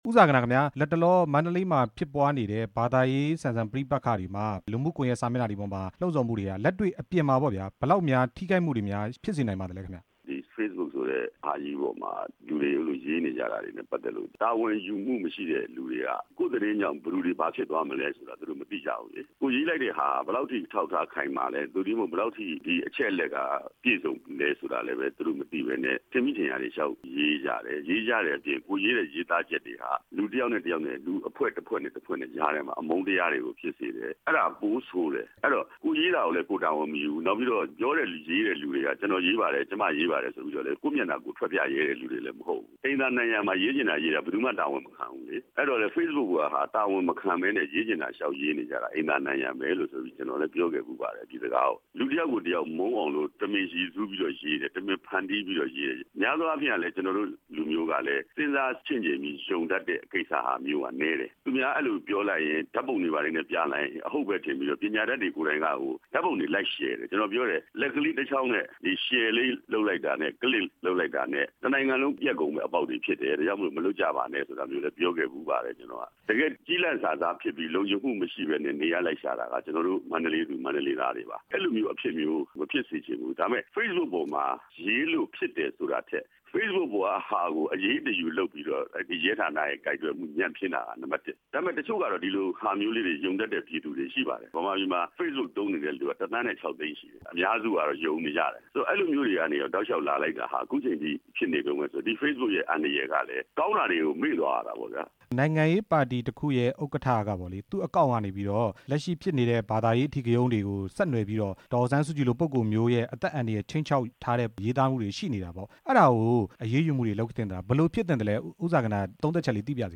Facebook ပေါ်မှ ရေးသားမှုများအကြောင်း ဦးဇာဂနာနဲ့ မေးမြန်းချက်